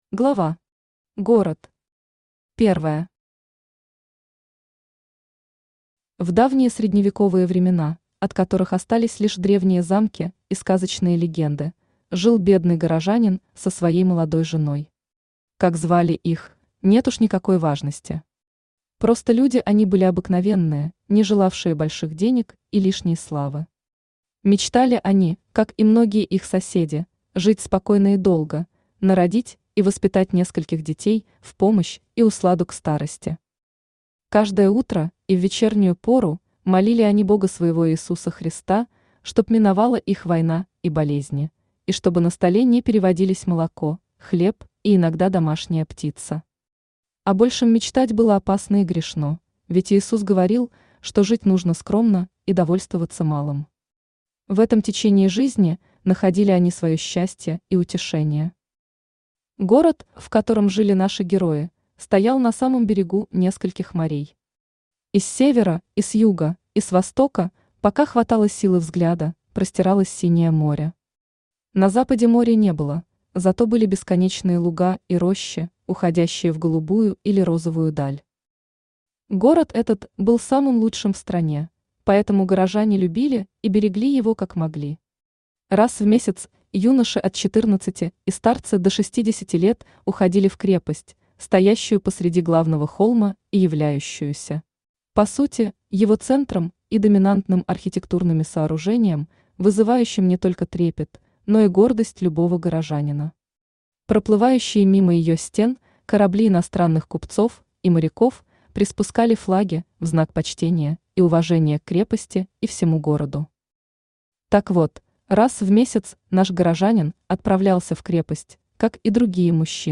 Аудиокнига Легенда о витязе Актеоне и волшебном Змее | Библиотека аудиокниг
Aудиокнига Легенда о витязе Актеоне и волшебном Змее Автор Александр Елизарэ Читает аудиокнигу Авточтец ЛитРес.